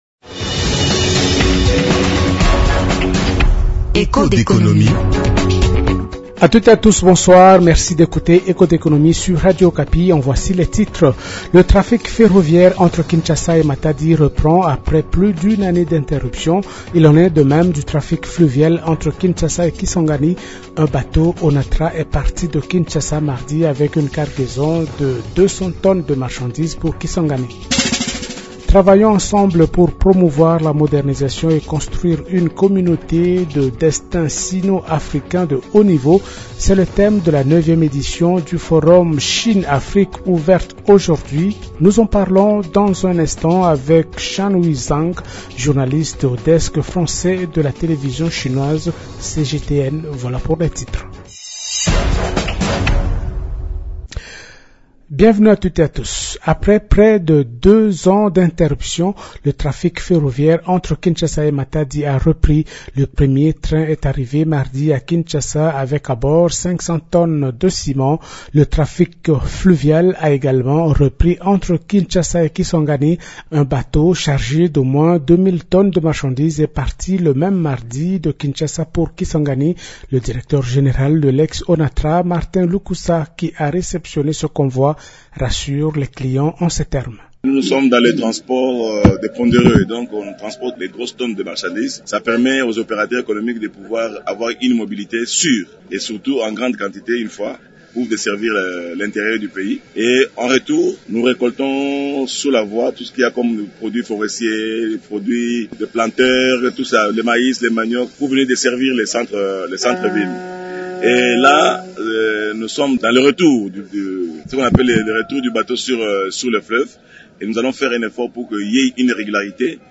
Autres sujets abordés dans le magazine Echos d’économie du mercredi 4 septembre : -Le ministre du Portefeuille, Jean Lucien Busa sur la reprise du trafic ferroviaire entre Kinshasa et Matadi ainsi que celle du trafic fluvial entre Kinshasa et Kisangani.